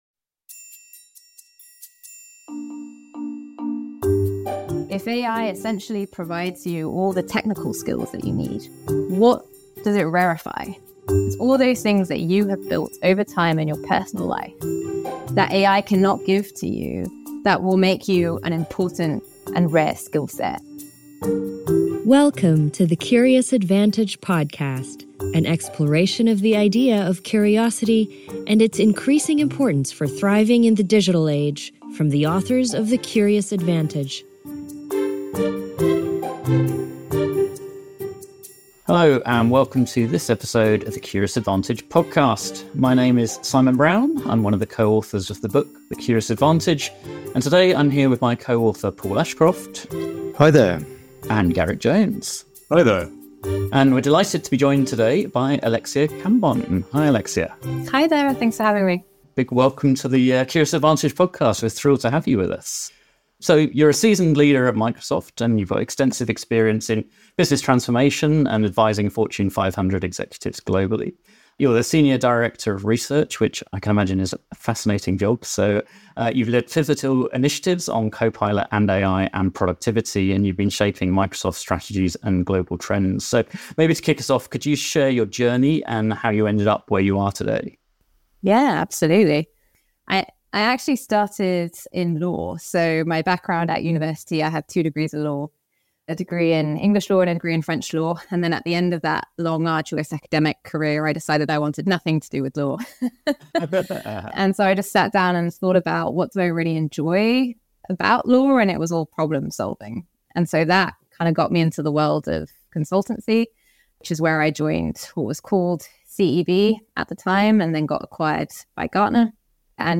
In this conversation, the speakers explore the multifaceted impact of AI on critical thinking, education, and workplace dynamics. We discuss the potential biases inherent in AI models, the transformative role of AI in providing a safe learning environment for students, and the emergence of power users who leverage AI for enhanced productivity.